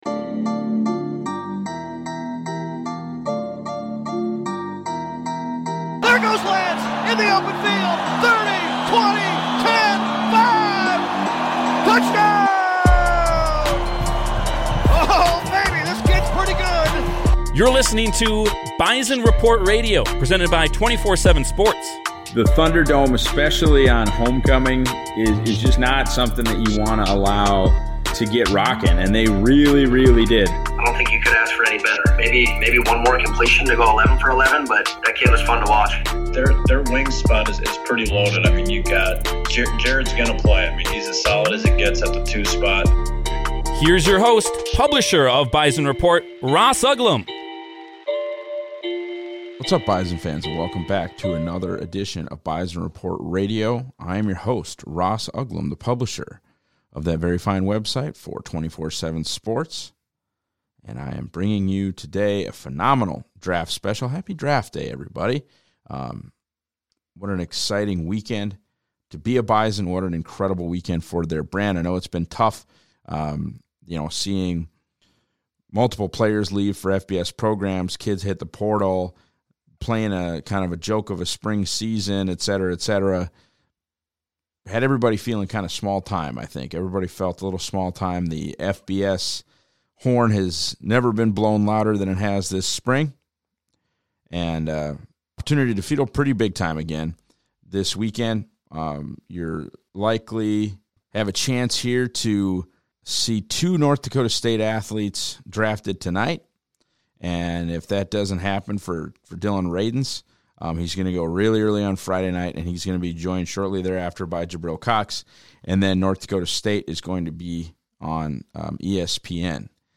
chats with two bona fide experts on two of NDSU's main draft prospects for this weekend.